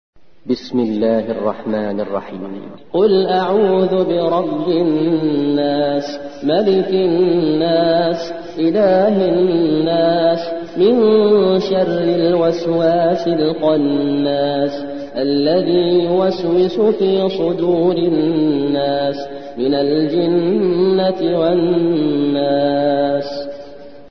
114. سورة الناس / القارئ